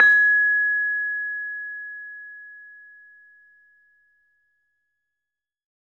LAMEL G#5 -R.wav